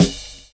Snare (26).wav